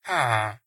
Sound / Minecraft / mob / villager / idle1.ogg